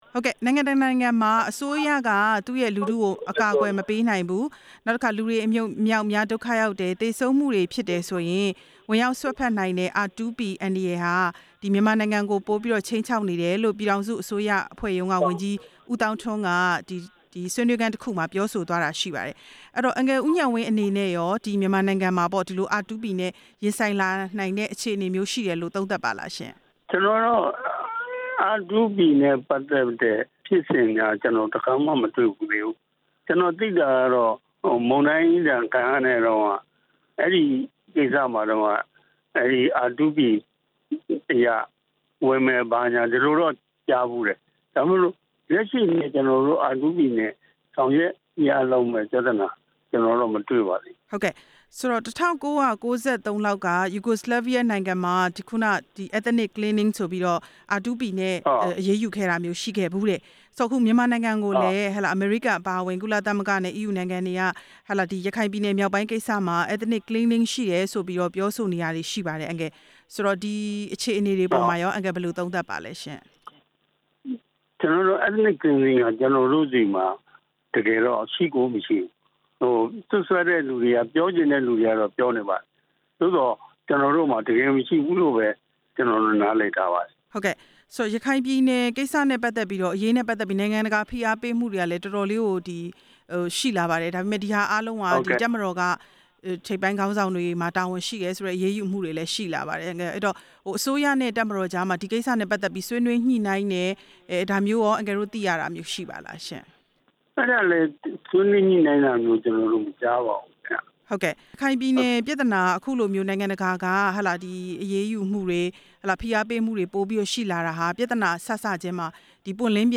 R2P အကြောင်းမေးမြန်းချက်